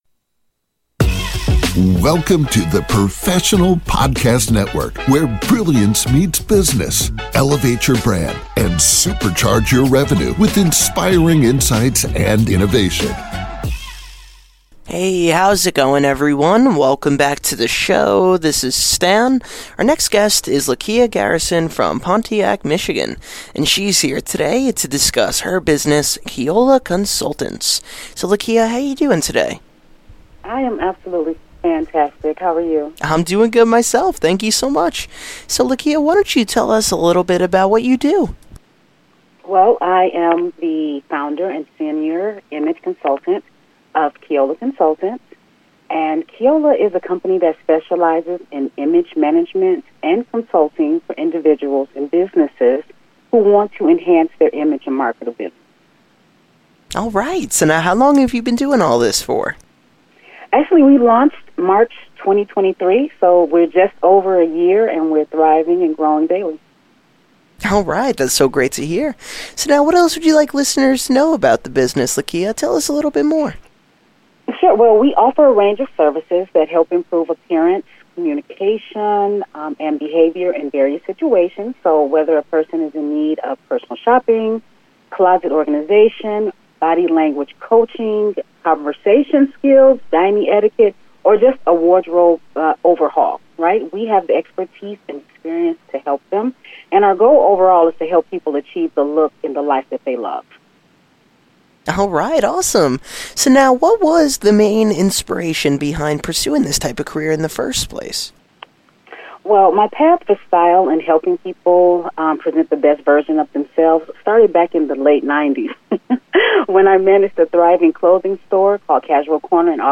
Podcast Interview 2024